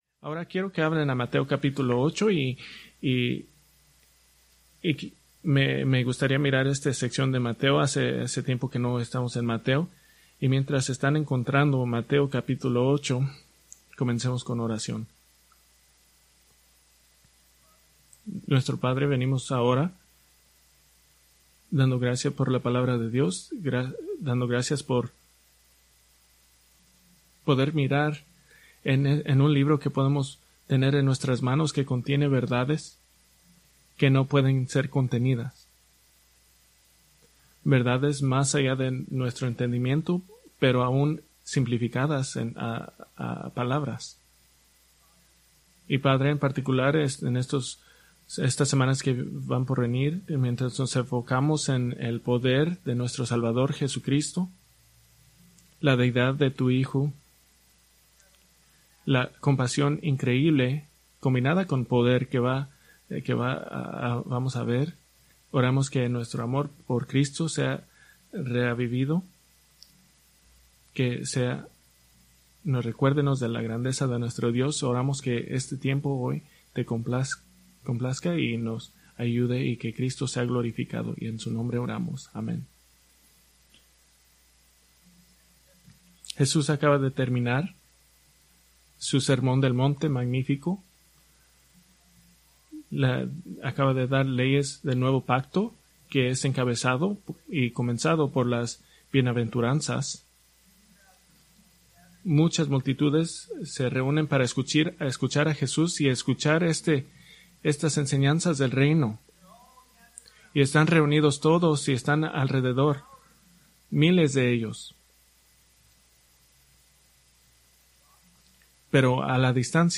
Preached September 1, 2024 from Mateo 8:1-4